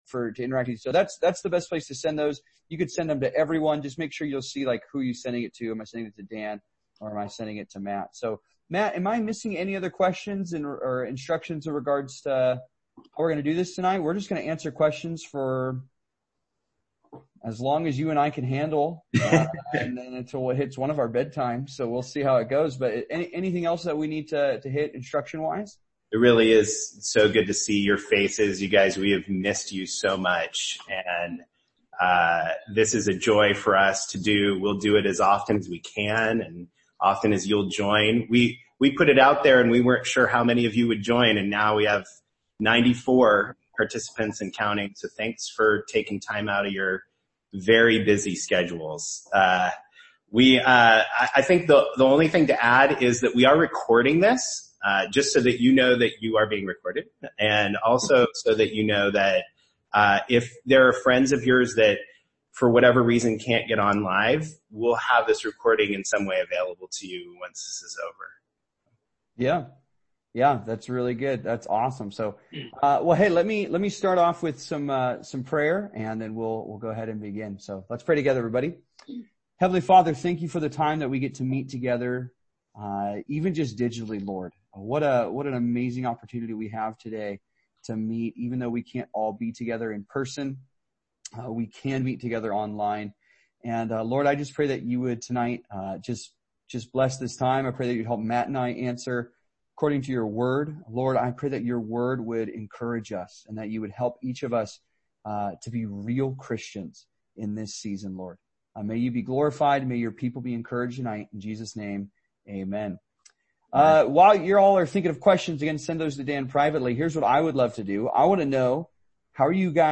180 Q&A over Zoom